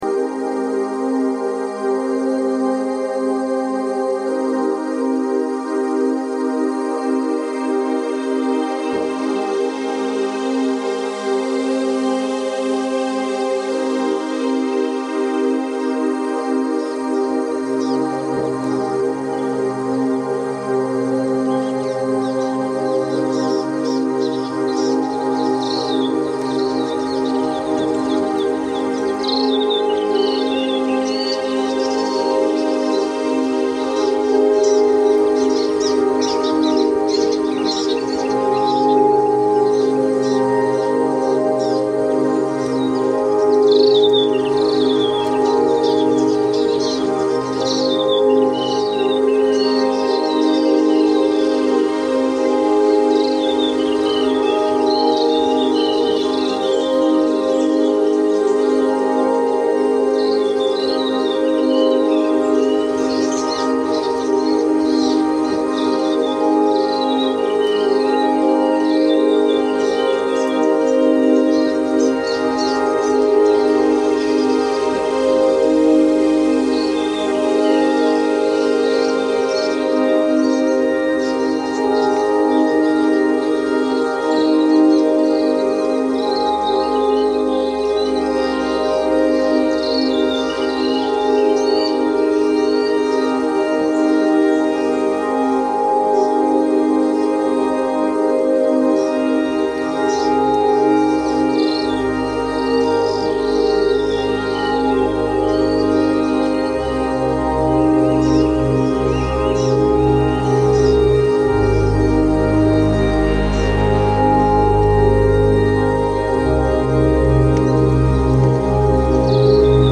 Okarito soundscape reimagined by Cities and Memory.